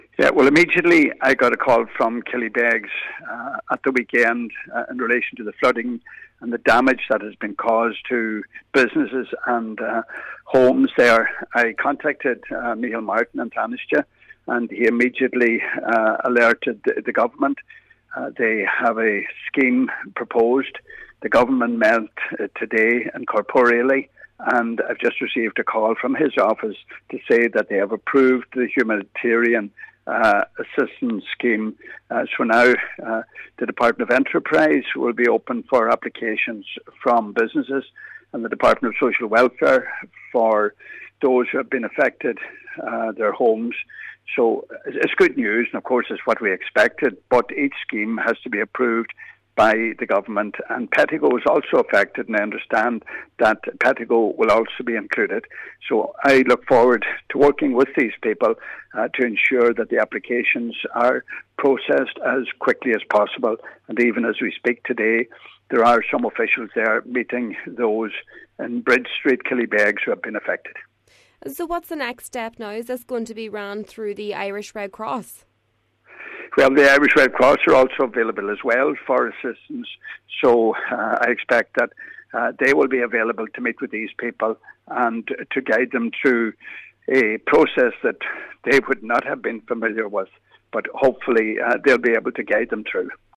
Fianna Fáil General Election candidate Pat ‘the Cope’ Gallagher says he has received the confirmation from government buildings: